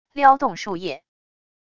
撩动树叶wav音频